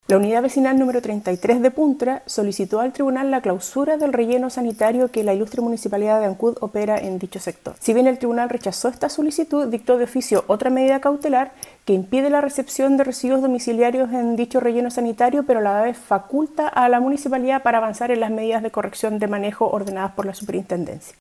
Sobre la determinación adoptada por el Tribunal Ambiental de Valdivia, entregó declaraciones la ministra de dicha instancia, Sibel Villalobos.